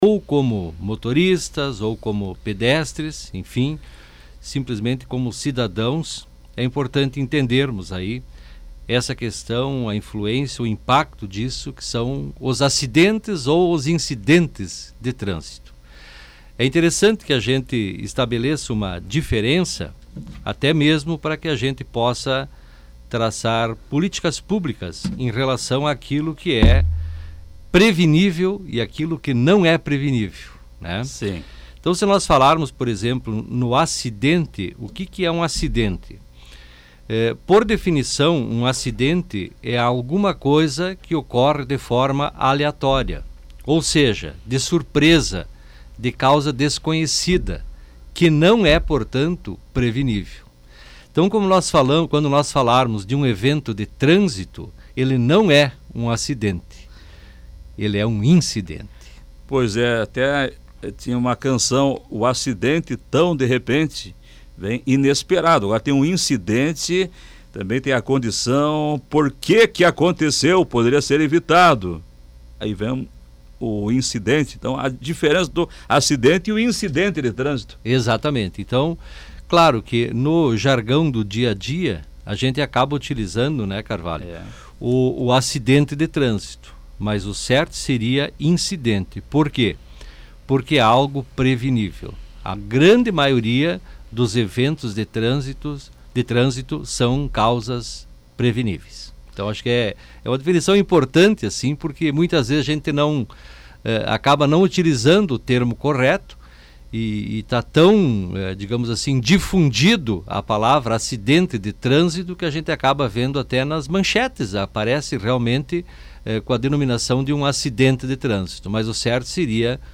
com grande participação dos ouvintes.